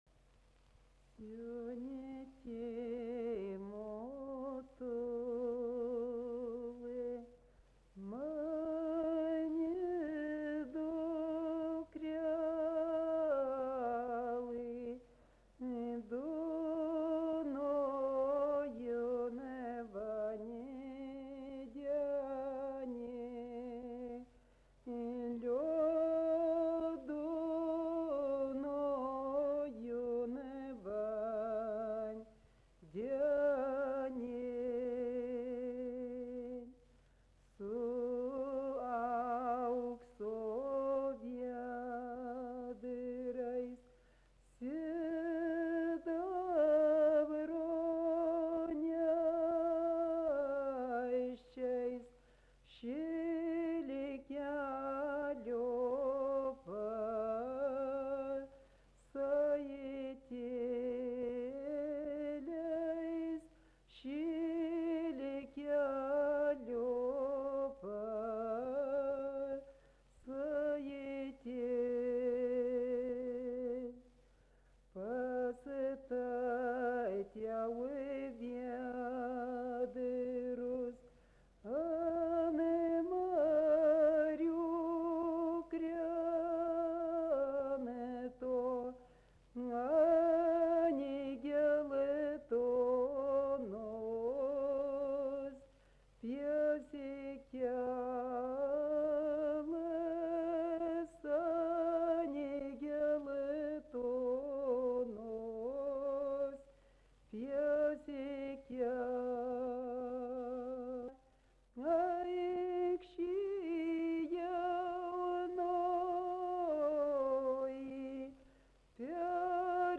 Erdvinė aprėptis Dargužiai
Atlikimo pubūdis vokalinis
dainuoja dviese, dviem balsais